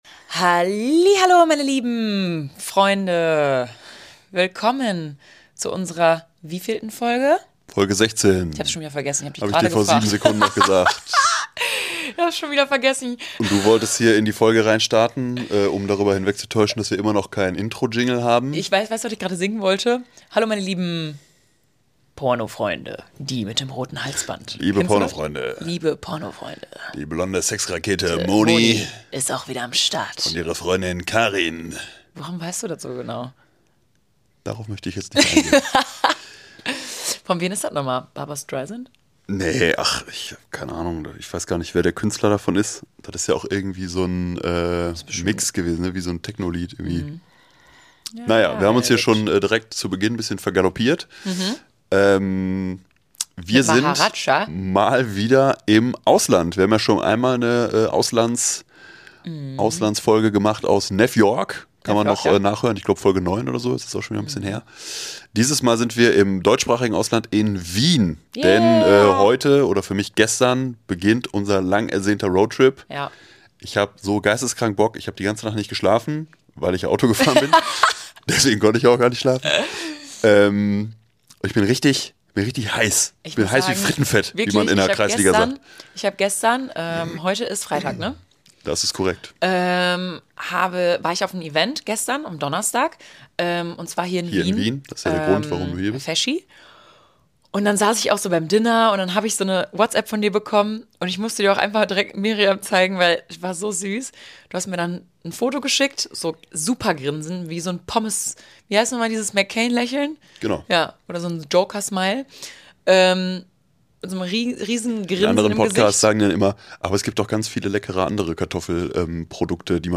in einem Hotel in der Wiener Innenstadt. Von dort aus geht es auf einen 2-wöchigen Roadtrip.